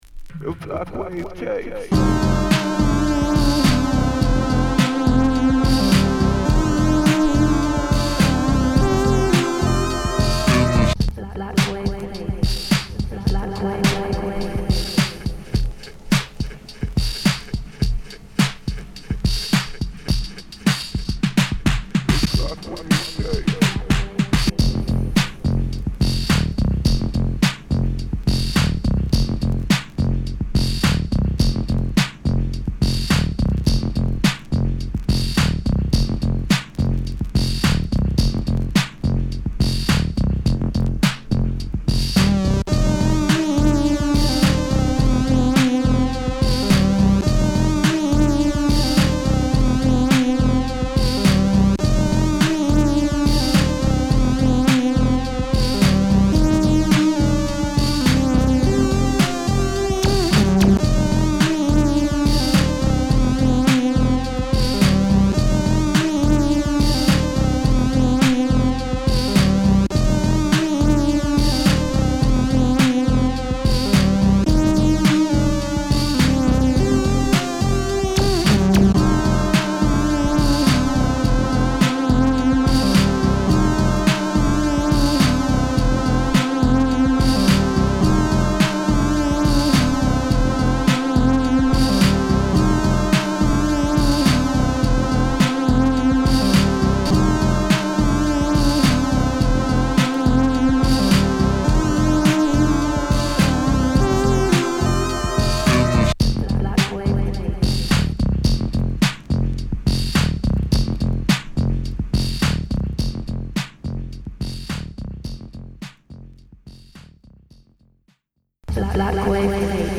ダークでハードなトラックを満載！